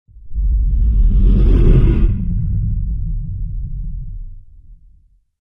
Звук полета ракеты в космическом пространстве